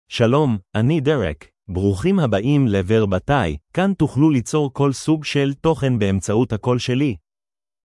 MaleHebrew (Israel)
Derek is a male AI voice for Hebrew (Israel).
Voice sample
Derek delivers clear pronunciation with authentic Israel Hebrew intonation, making your content sound professionally produced.